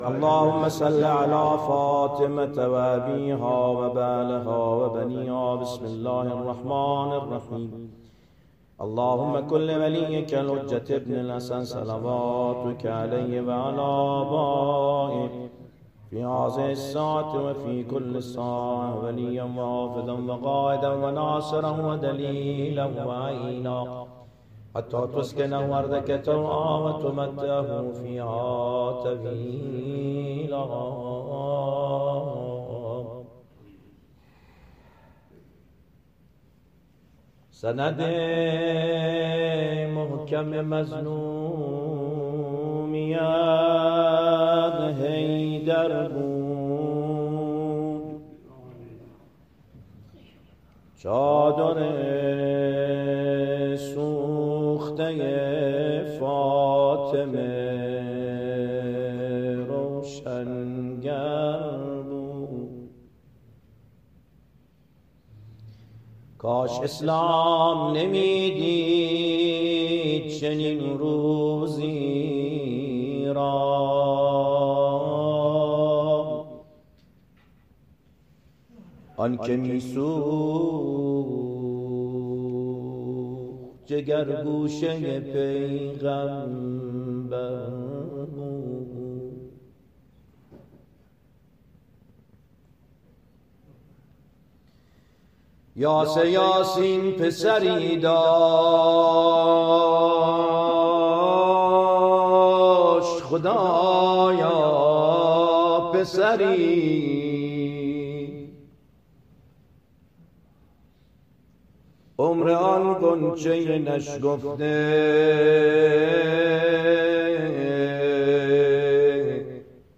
با مداحی